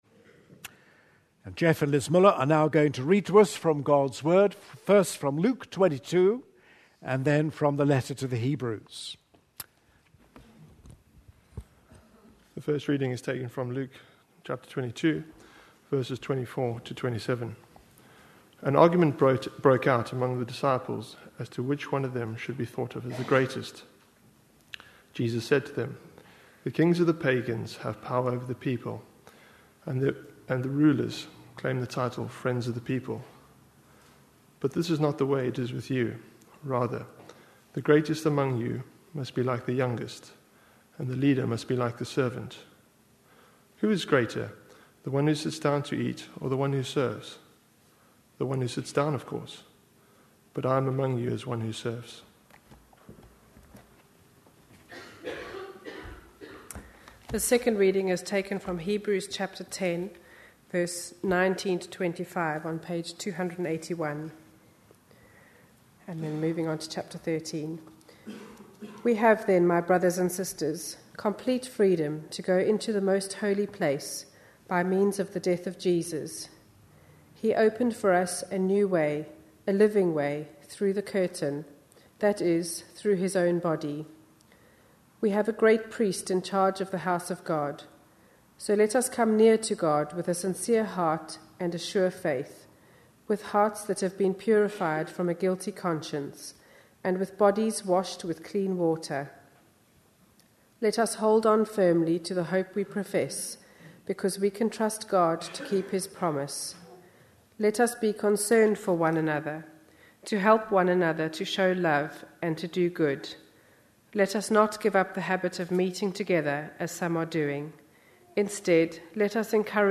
A sermon preached on 25th March, 2012, as part of our Looking For Love (10am Series) series.